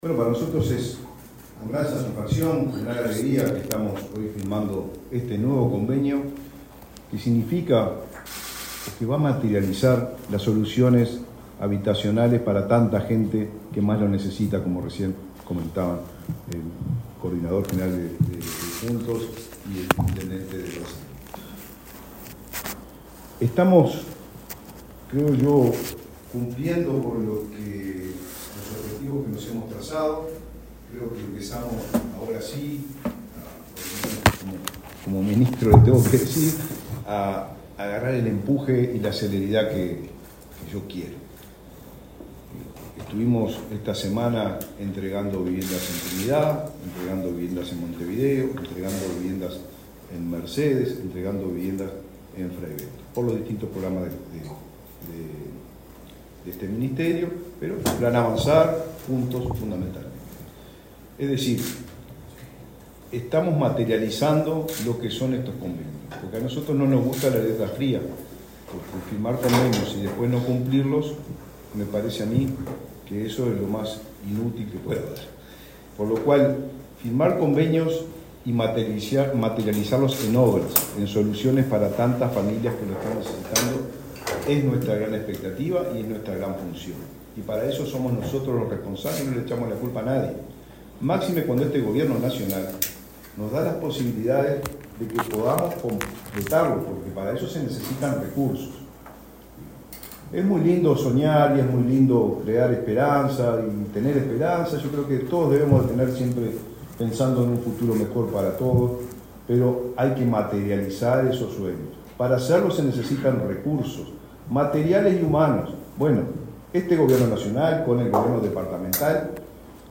Palabras del ministro de Vivienda, Raúl Lozano
El ministro de Vivienda, Raúl Lozano, firmó, este viernes 15 en Montevideo, un convenio con el intendente de Paysandú, Nicolás Olivera, para el